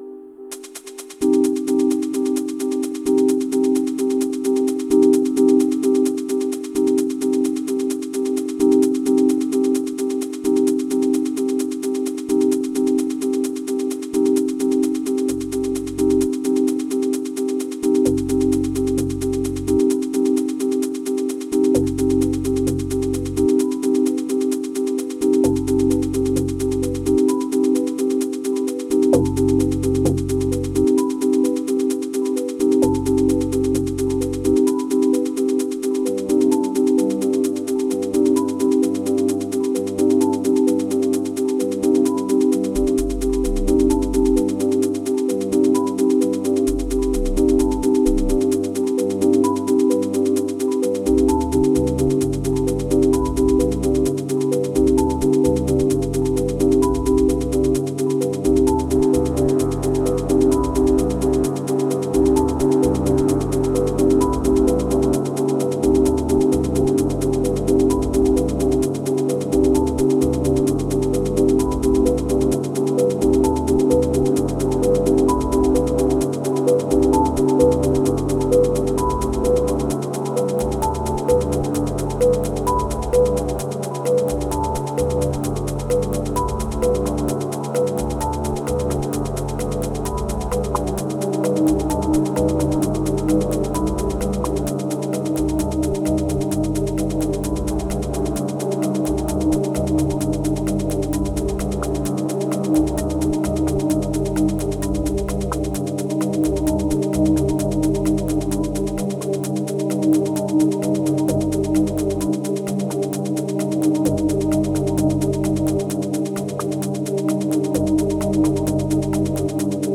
836📈 - 53%🤔 - 65BPM🔊 - 2020-07-09📅 - 223🌟
Ambient Furniture Boring Summer Abstract Moods Deep